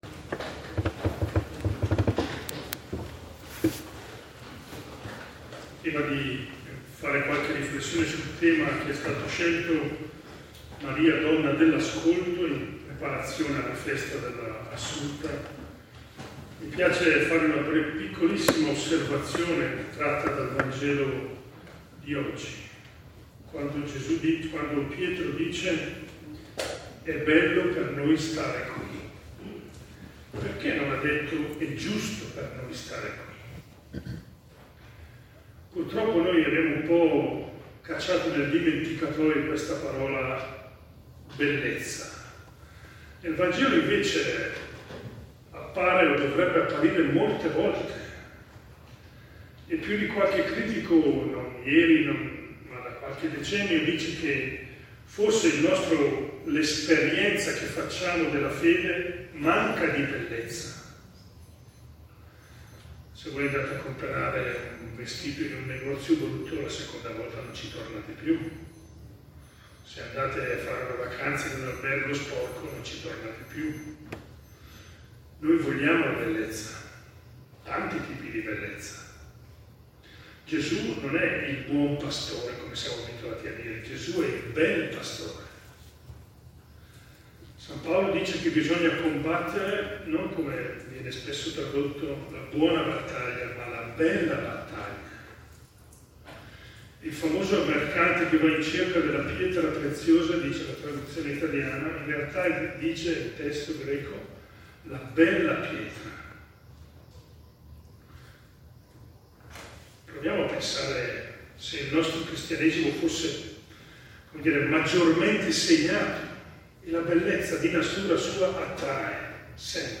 Celebrazione del 6 agosto 2025
riflessione: Maria, Vergine dell’ascolto